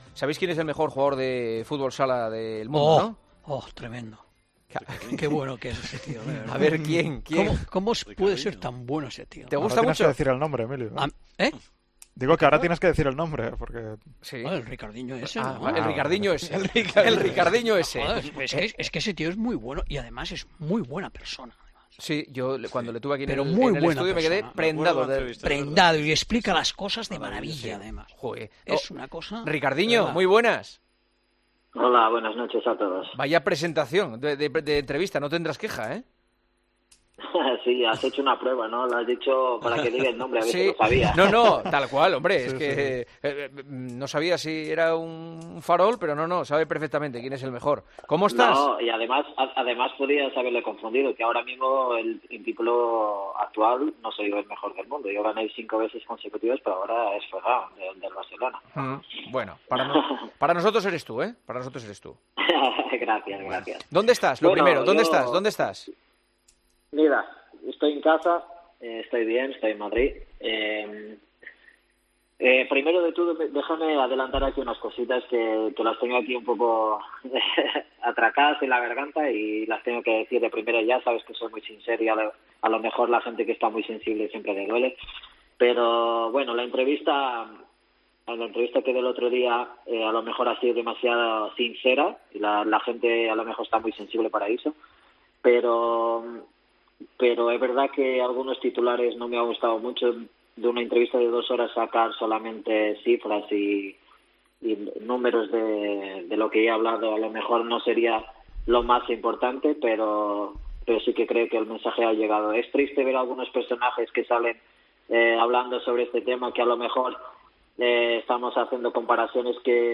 AUDIO - ENTREVISTA A RICARDINHO, JUGADOR DE MOVISTAR INTER, EN EL PARTIDAZO DE COPE